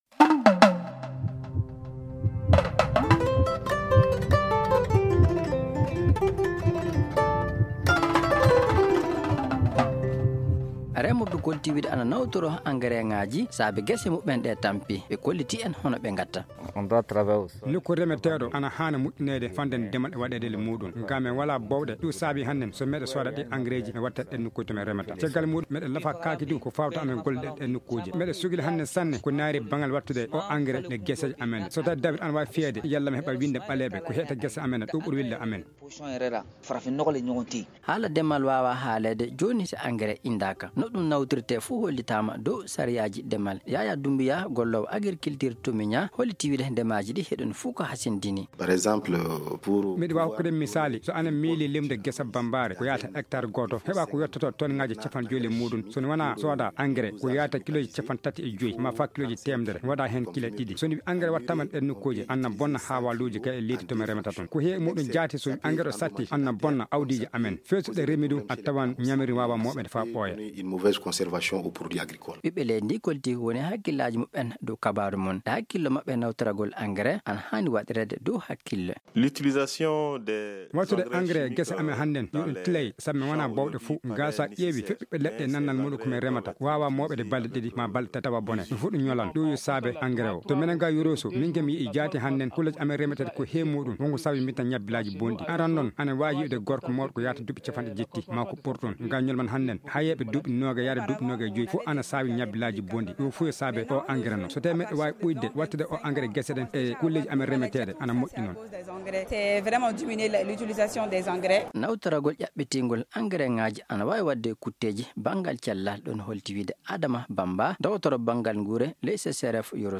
Notre magazine santé s’intéresse au sujet.